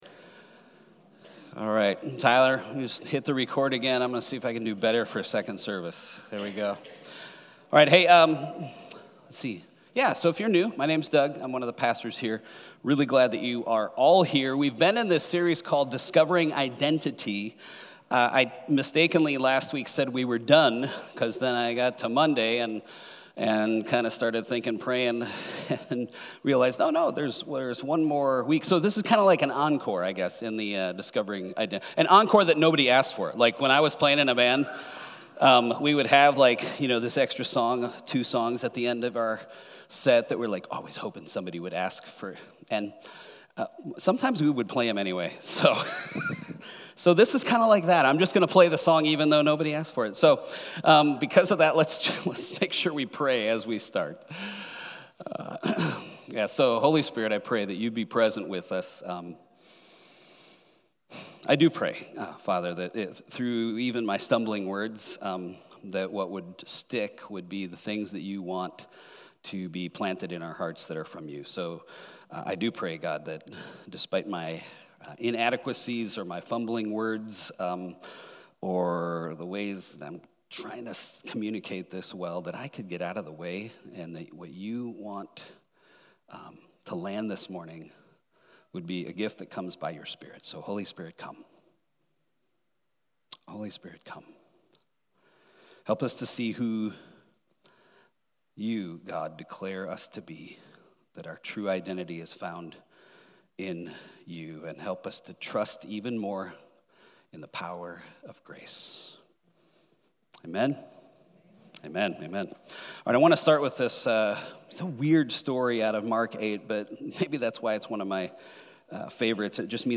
Weekly messages from HOPE Covenant Church in Chandler AZ